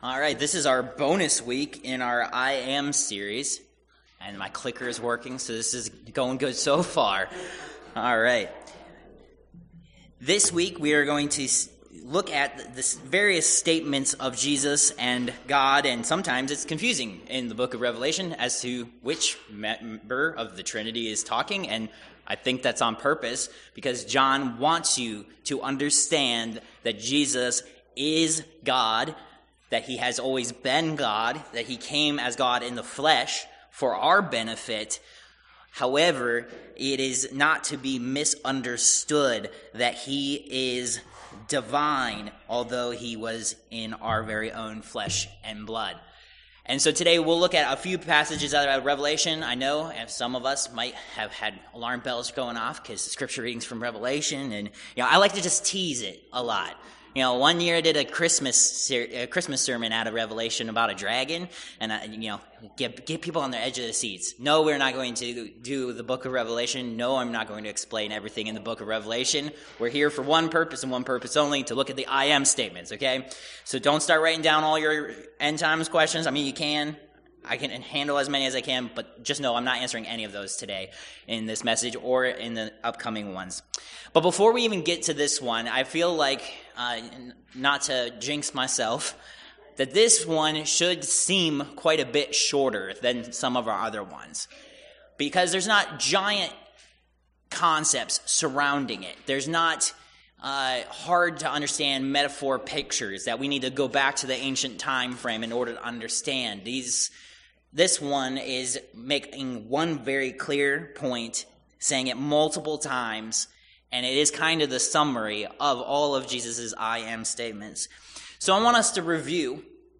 I AM Passage: Rev. 1:1-8 Service Type: Worship Service « Protected